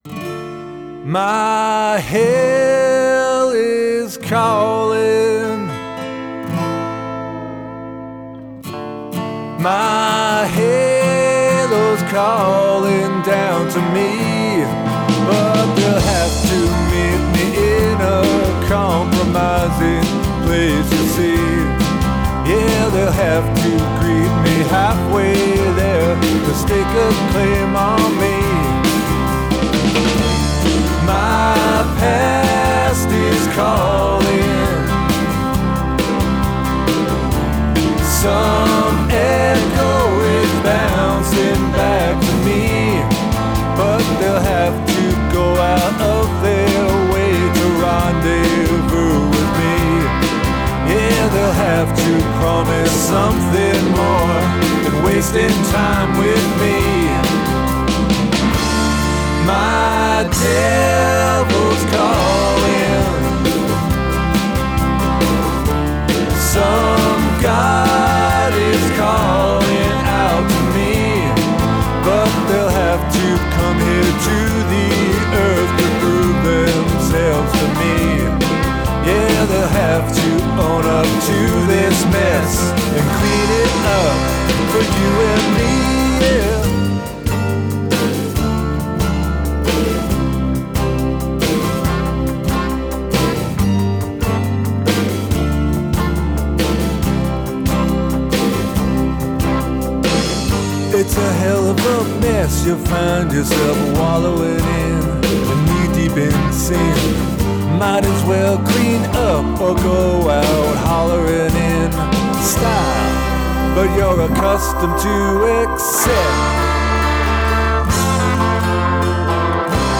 bass, mandolin, vocals
guitars and vocals
keys
Americana or roots rock camp of musical styles